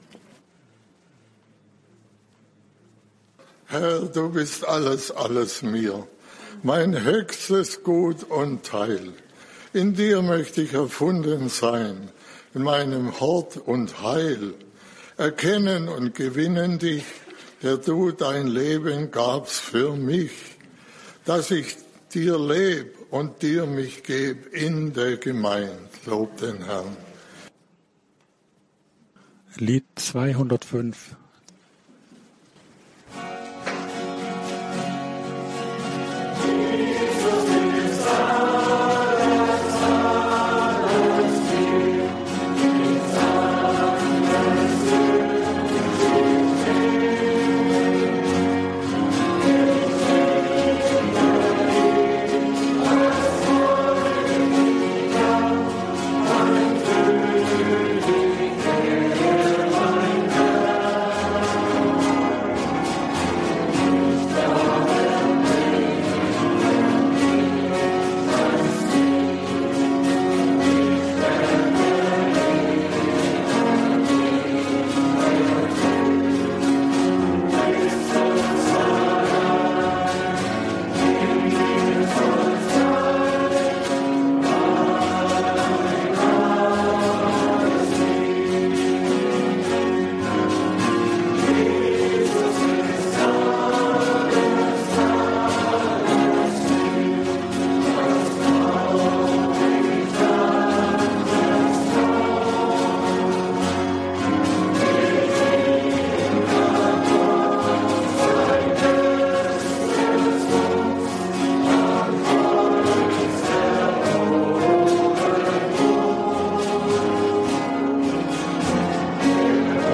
Gemeindeversammlung 8.2.2026